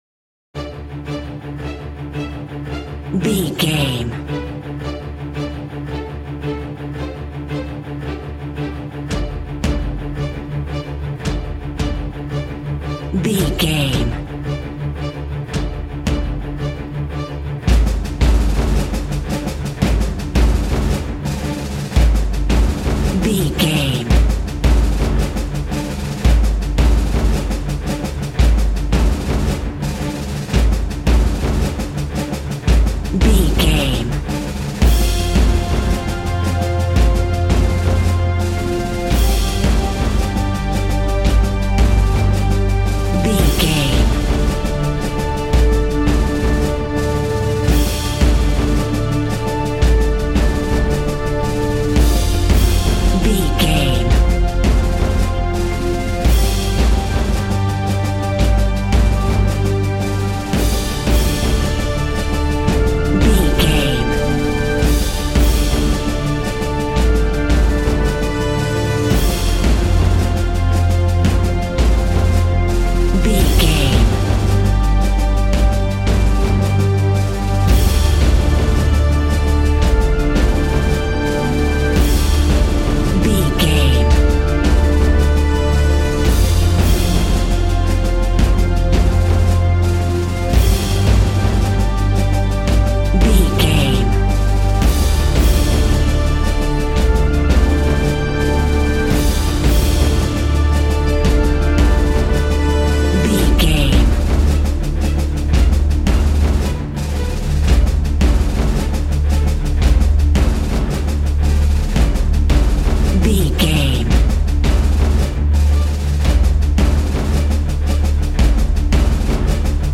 Epic / Action
Fast paced
In-crescendo
Uplifting
Aeolian/Minor
strings
brass
percussion
synthesiser